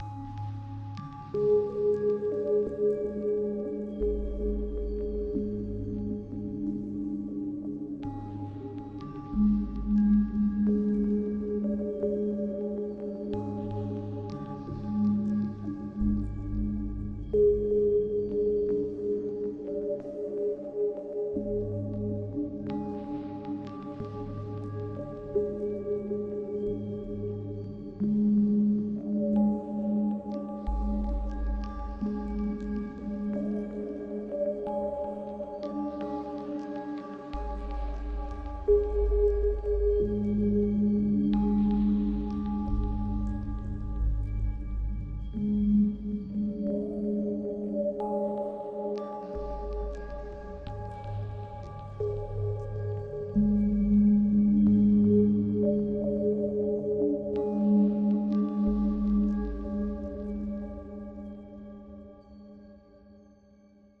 Звуковой ландшафт: Космический снег
Звуковой ландшафт